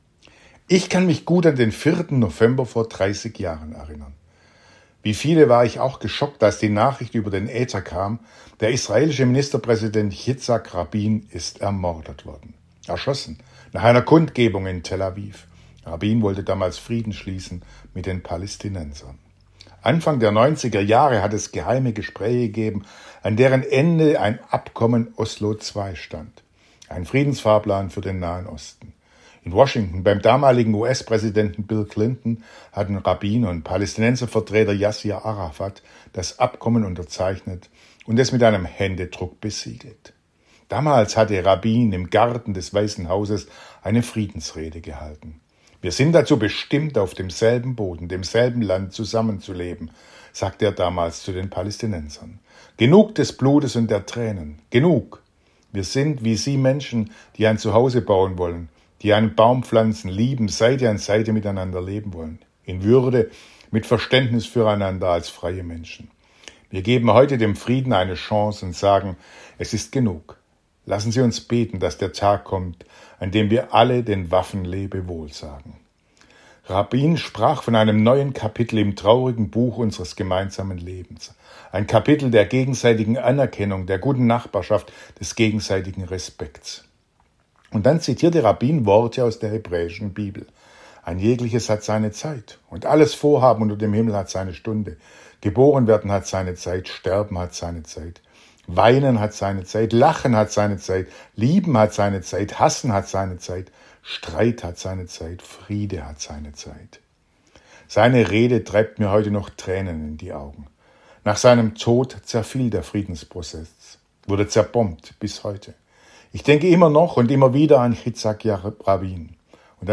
Radioandacht vom 4. November